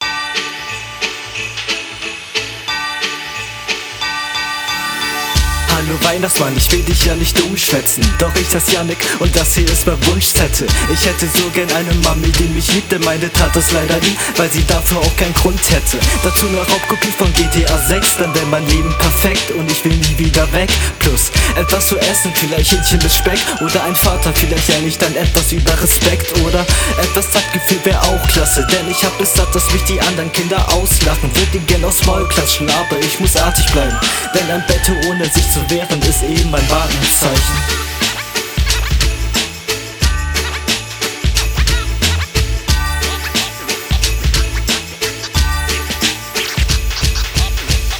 Auch stabil gerappt. mag die Idee sehr.